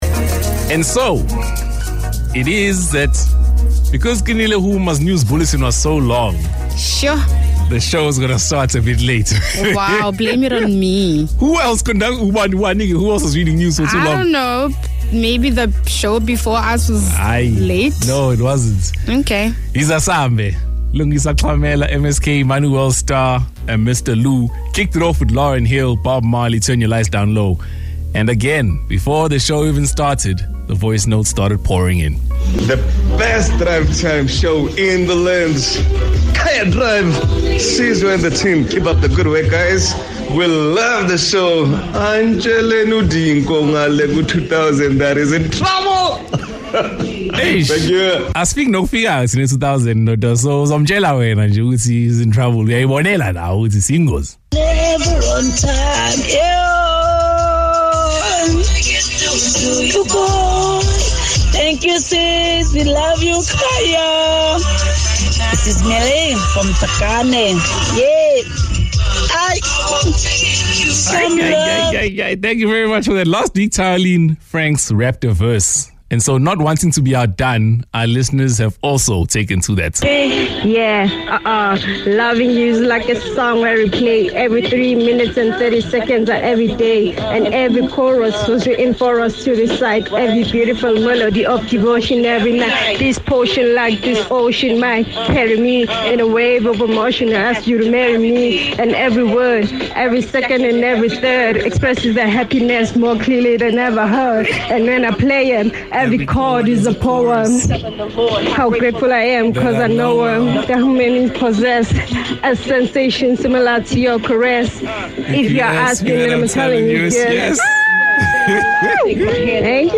Listen to the team talk about side hustles: